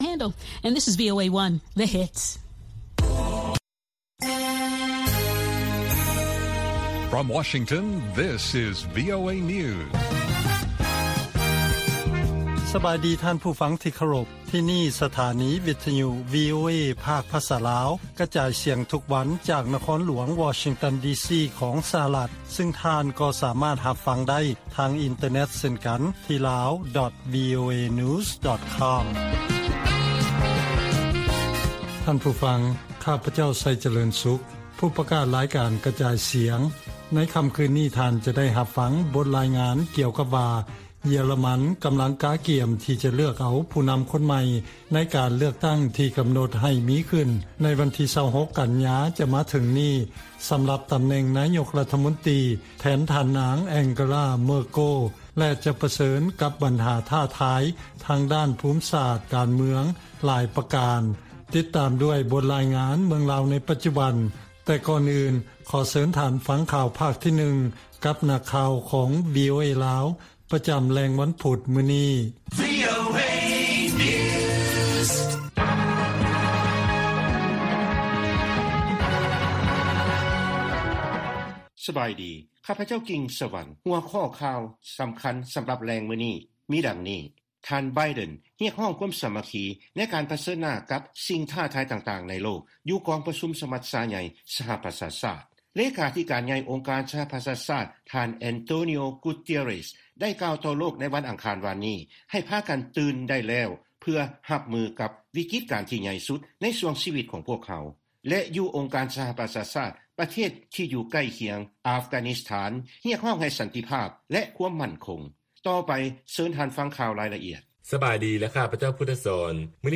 ວີໂອເອພາກພາສາລາວ ກະຈາຍສຽງທຸກໆວັນ, ຫົວຂໍ້ຂ່າວສໍາຄັນໃນມື້ນີ້ມີ: 1.ດ້ວຍຄຳສັນຍາກ່ຽວກັບ “ການທູດ ທີ່ແຂງຂັນ” ແລະ ປະຕິຍານທີ່ຈະບໍ່ສະ ແຫວງຫາ“ ສົງຄາມເຢັນຄັ້ງໃໝ່ນັ້ນ,” ປະທານາທິບໍດີສະຫະລັດທ່ານໂຈ ໄບເດັນ ໄດ້ໃຊ້ຄຳປາໄສຄັ້ງທຳອິດຂອງທ່ານ, 2.ເລຂາທິການໃຫຍ່ອົງການສະຫະປະຊາຊາດທ່ານແອນໂຕນິີໂອ ກູເຕເຣັສ ໄດ້ກ່າວຕໍ່ໂລກໃນວັນອັງຄານວານນີ້ ໃຫ້ພາກັນ “ຕື່ນໄດ້ແລ້ວ” ເພື່ອຮັບມືກັບວິກິດການທີ່ໃຫຍ່ສຸດ ໃນຊ່ວງຊີວິດຂອງພວກເຮົາ, 3.ບັນດາປະເທດທີ່ຢູ່ໃກ້ຄຽງຂອງ ອັຟການິສຖານ ໄດ້ສະແດງປະຕິກິລິຍາ ຕໍ່ການ ຖອນທະຫານ ສະຫະລັດ ໃນເດືອນທີ່ຜ່ານມາ ແລະ ການເຂົ້າຄວບຄຸມປະເທດ ຂອງກຸ່ມຕາລີບານ ແລະຂ່າວສໍາຄັນອື່ນໆອີກ.